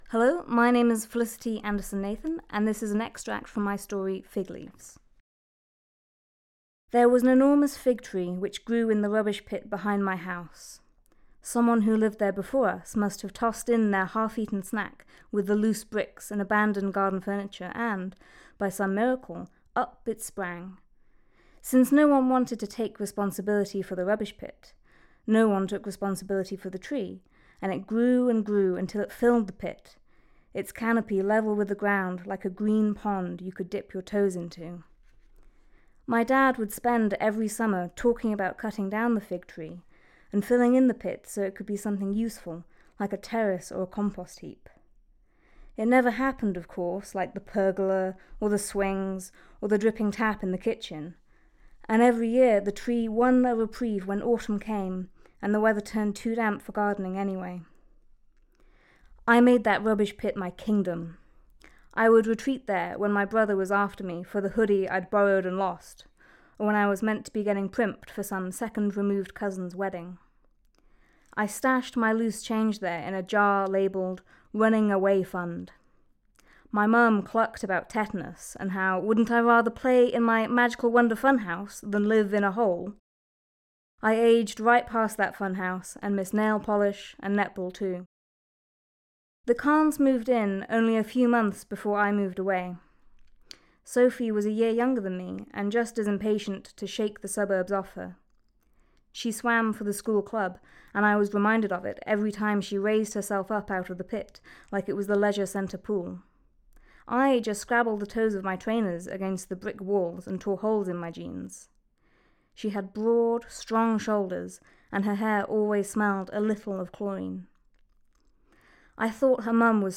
reads an extract